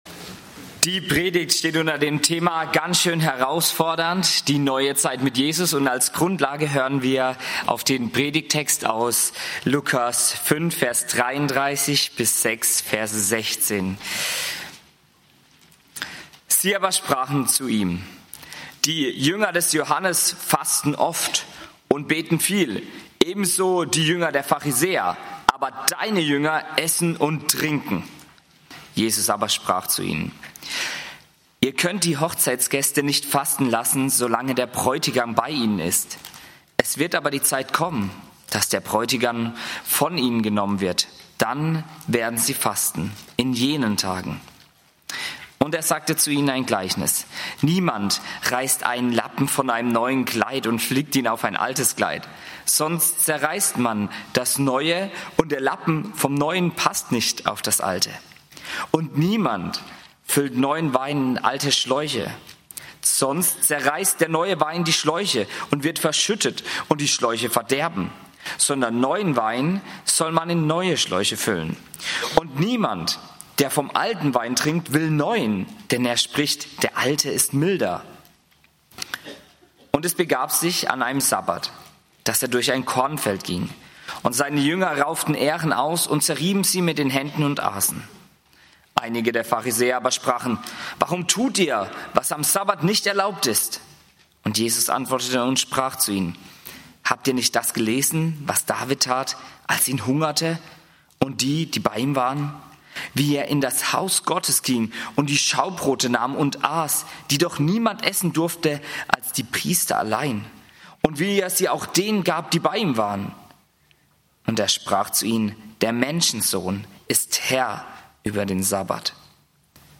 Ganz schön herausfordernd - Die neue Zeit mit Jesus (Lk. 5, 33 - 6, 16) - Gottesdienst